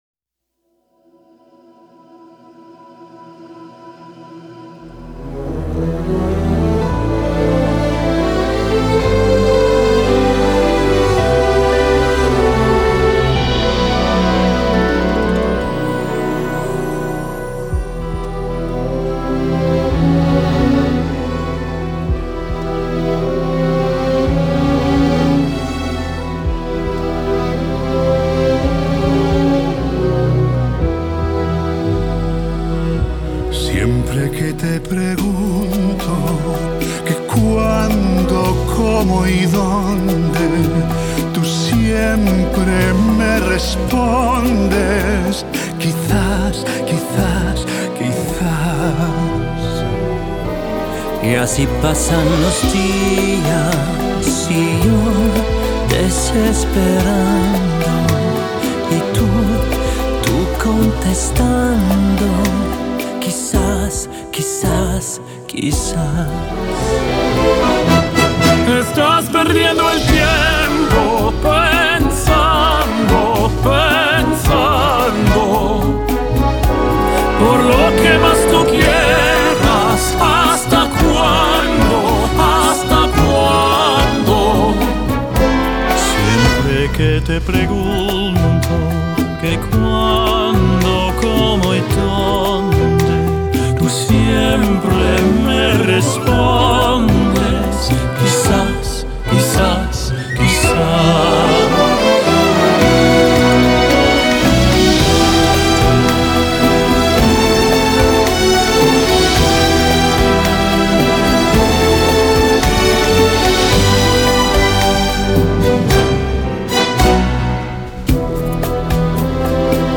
Genre: Classical Crossover